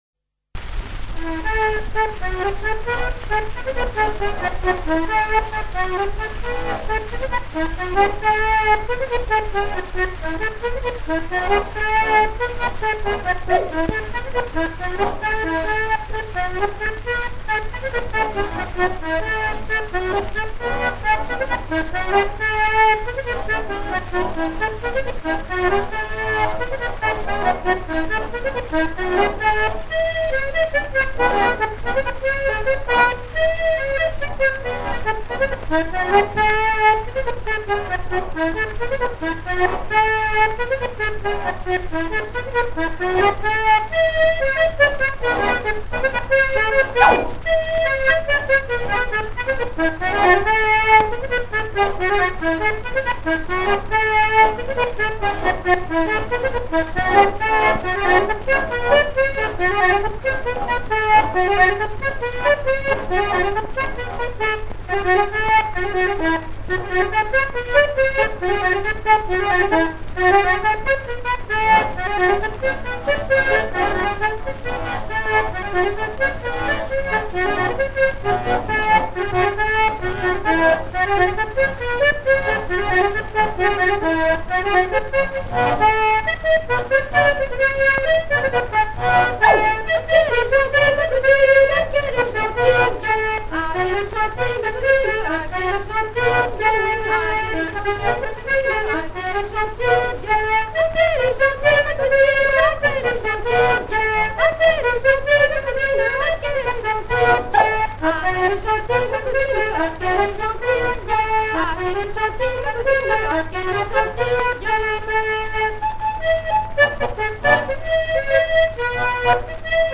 Danses maraîchines, courantes
78t. pyral
Prises de sons diverses
Pièce musicale inédite